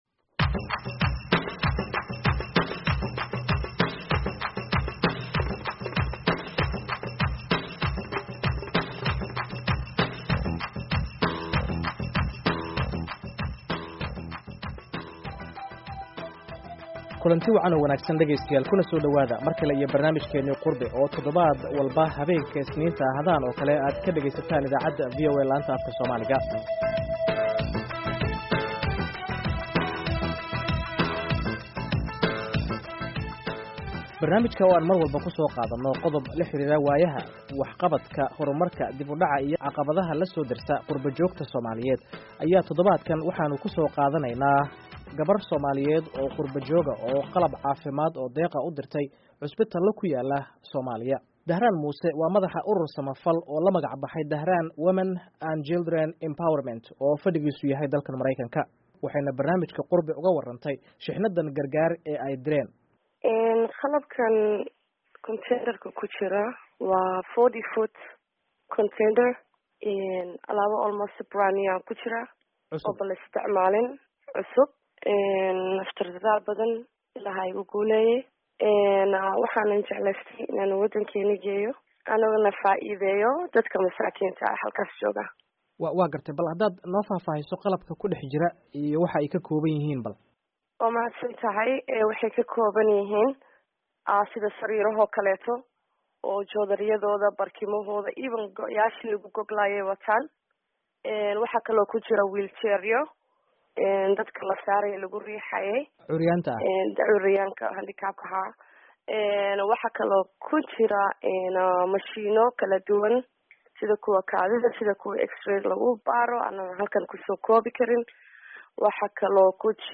Barnaamijkeenii qurbe, oo aad todobaad walba habeenka isniinta ah aad ka dhageysataan idaacadda VOA laanta afka Somaliga ayaan aalaaba ku soo qaadanaa qodob la xiriira waaya, waxqabadaka, horumarka, dib u dhaca iyo caqadaha la soo dersa quba joogta.